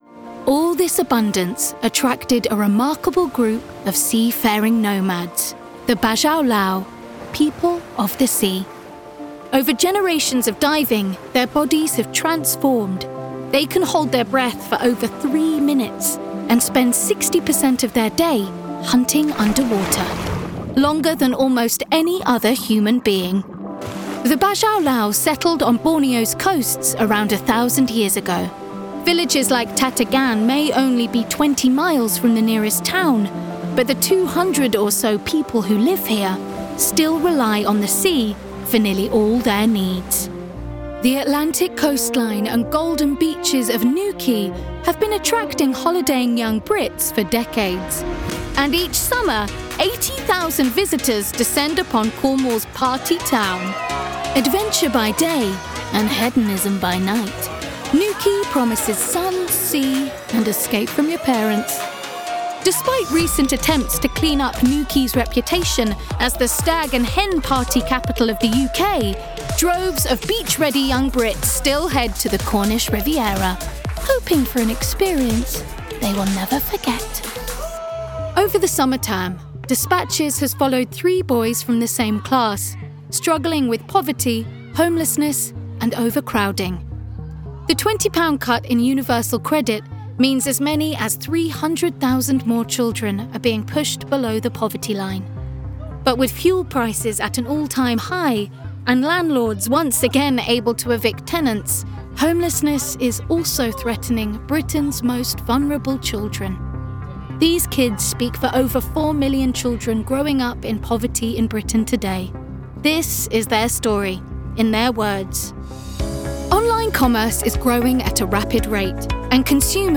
• Native Accent: London
• Home Studio